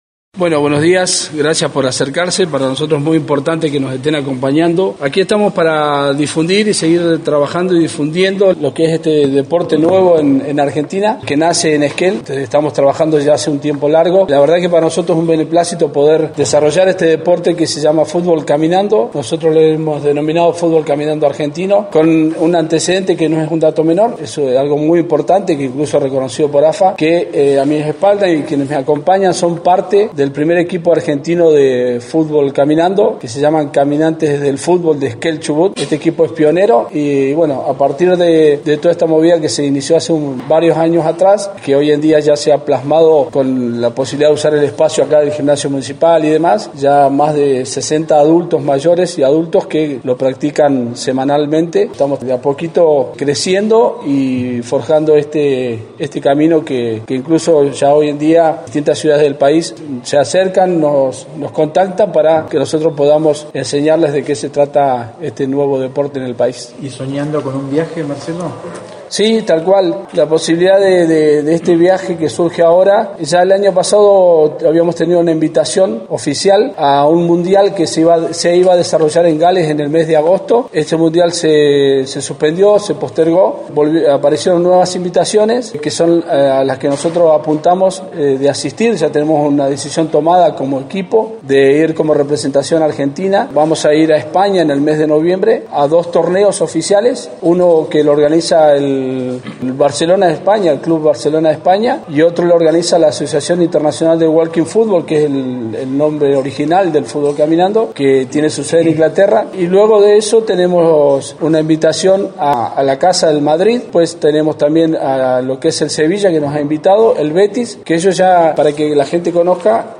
detalló en conferencia de prensa de que se trata esta oportunidad de viajar a visitar varios clubes de Europa.